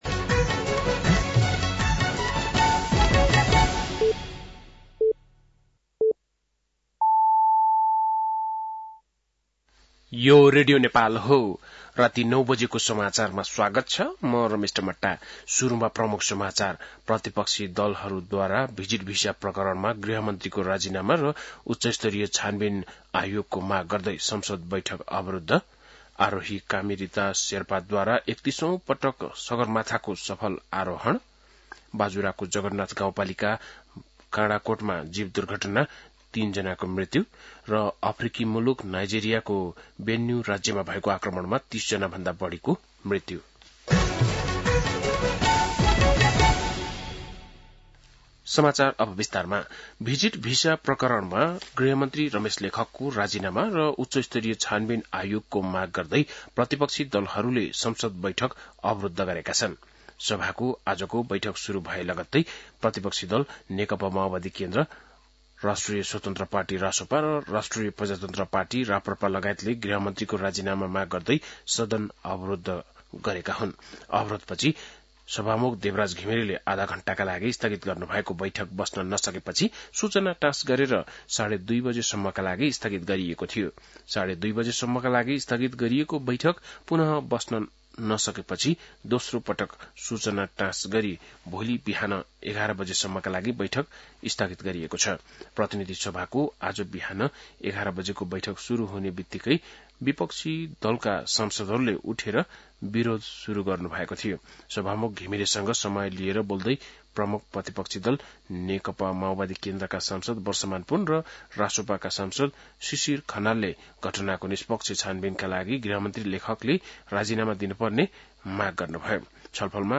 बेलुकी ९ बजेको नेपाली समाचार : १३ जेठ , २०८२